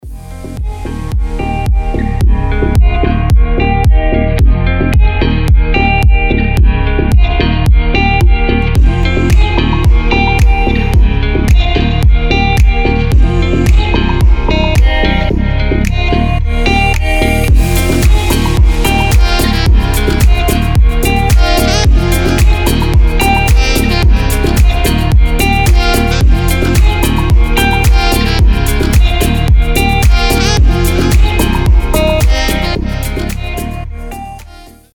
• Качество: 320, Stereo
гитара
deep house
без слов
красивая мелодия
Саксофон
Прекрасный deep